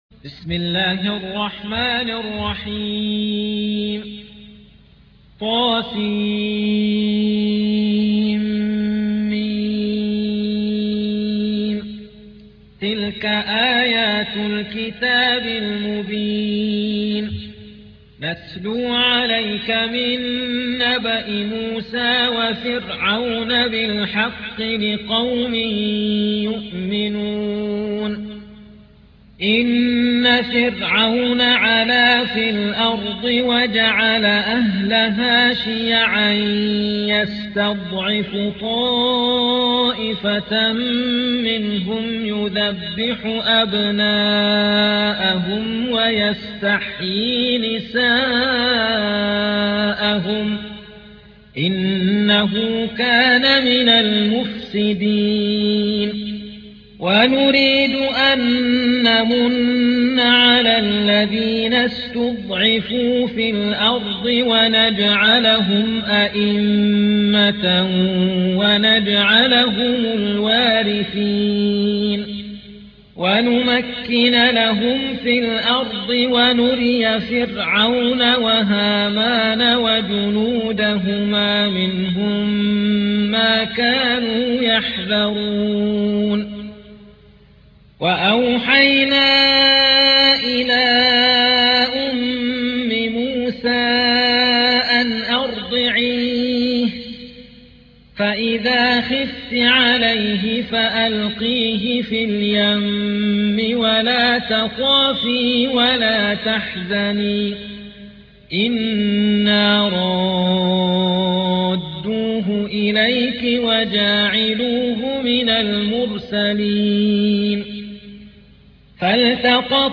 28. سورة القصص / القارئ